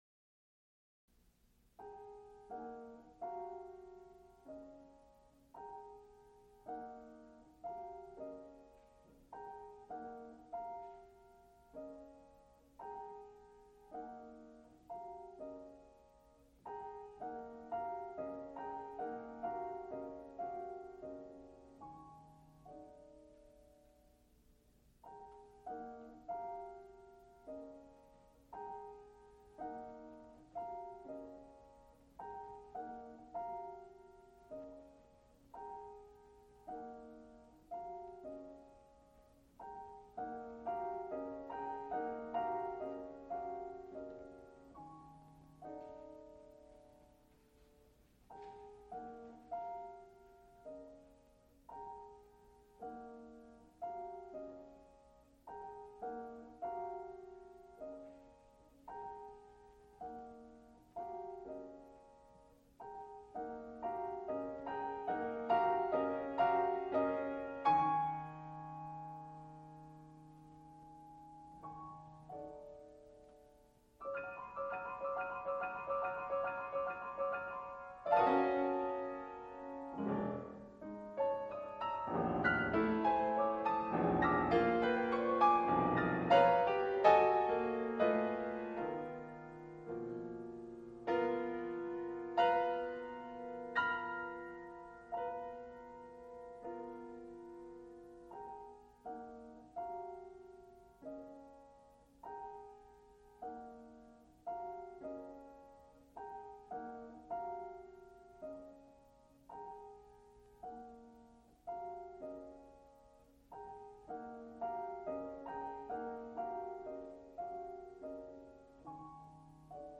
for flute, harpsichord, and Putney synthesizer
Extent 4 audiotape reels : analog, quarter track, 7 1/2 ips ; 7 in.
musical performances
Piano music
Flute music Songs (High voice) with piano
Flute and harpsichord music